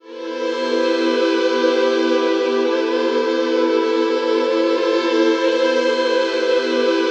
WEEPING 2 -L.wav